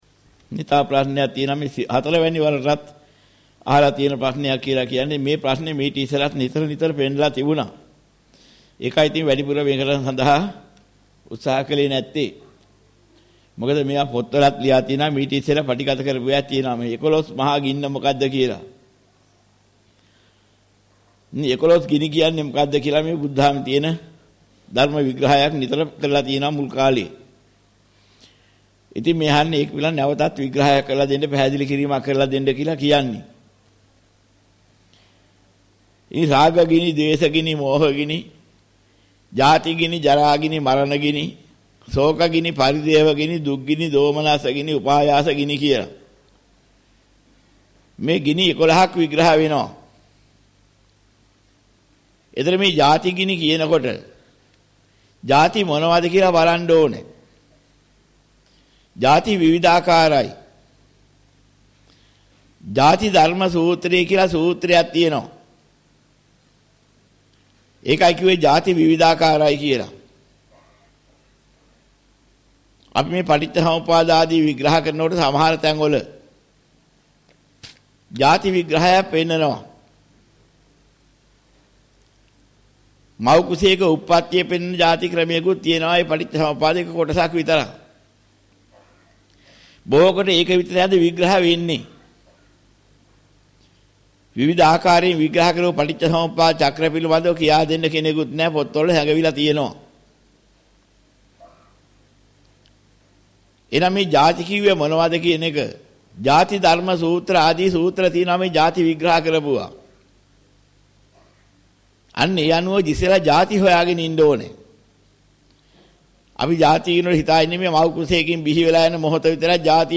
මෙම දේශනය